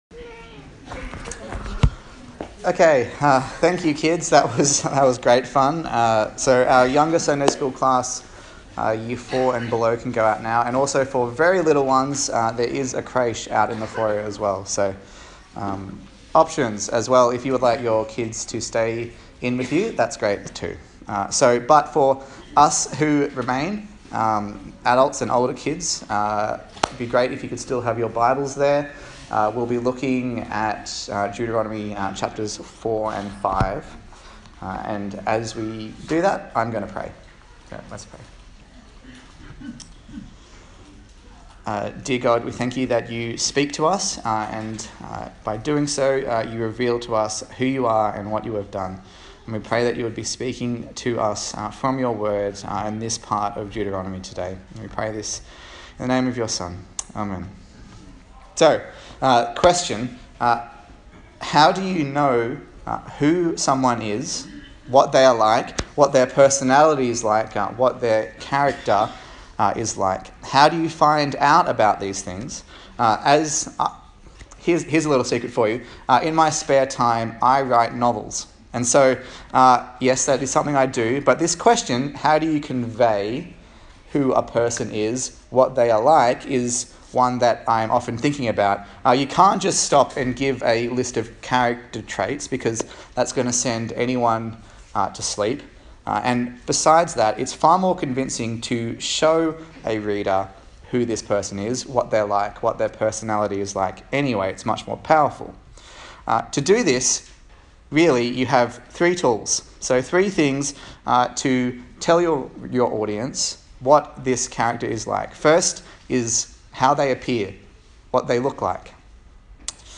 Deuteronomy Passage: Deuteronomy 5 Service Type: Sunday Morning